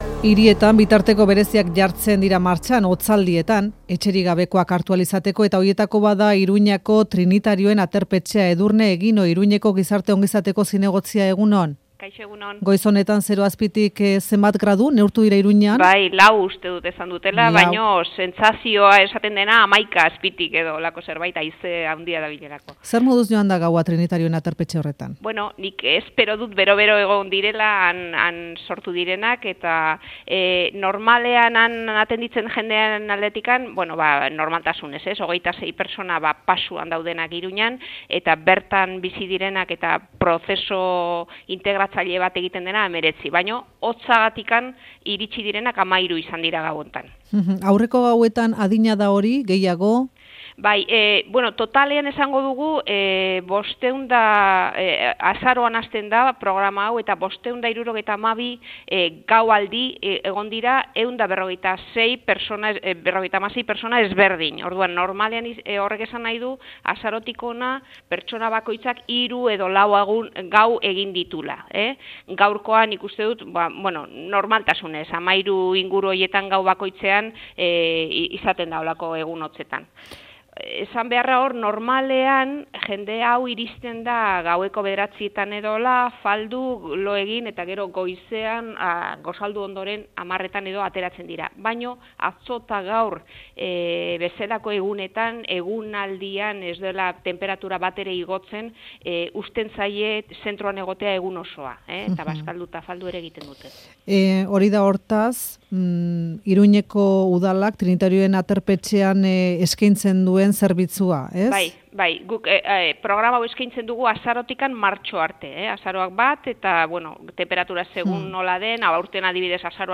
Audioa: Edurne Eginorekin Iruñeko gizarte gaietarako zinegotziarekin hitz egin dugu Faktorian.